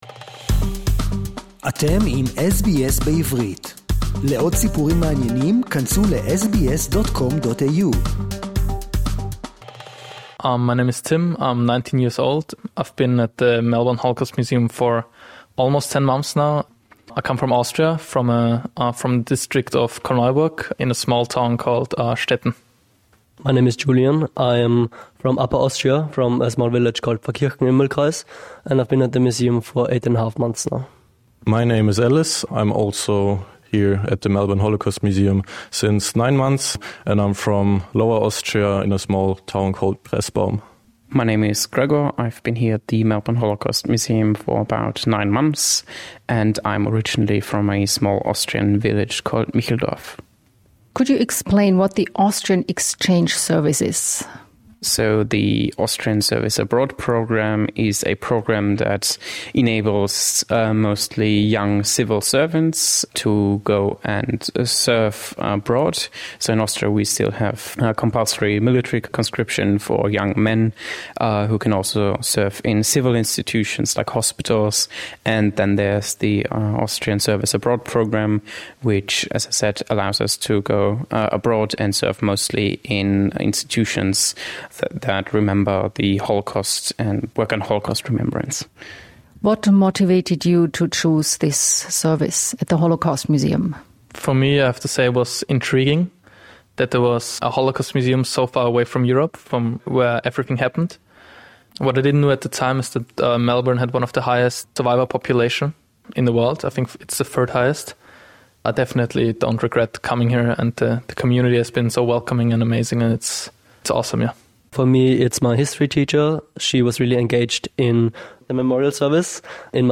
Part of the Austrian Service Abroad program, each of them chose to travel across the world to work with survivors, guide Australian students, and immerse themselves in Holocaust education. In conversation with SBS, they speak candidly about their motivations, the survivor stories that have left a lasting impact, and the emotional complexity of answering students’ questions about humanity’s darkest chapter.